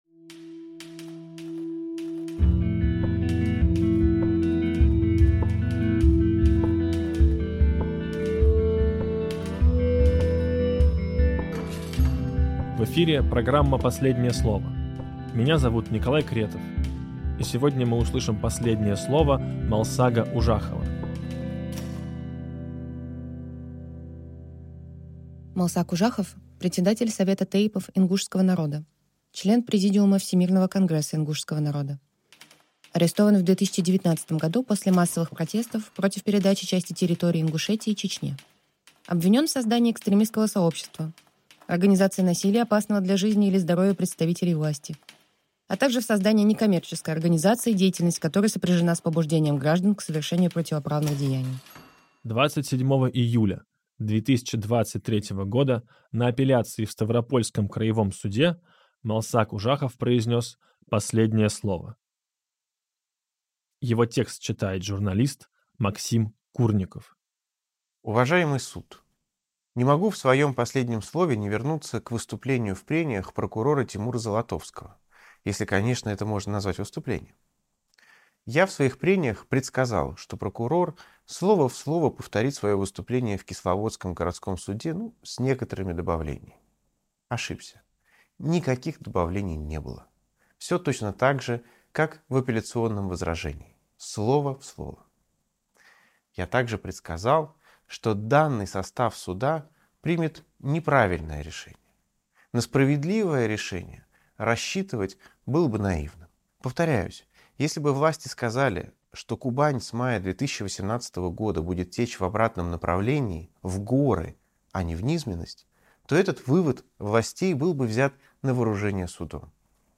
Читает Максим Курников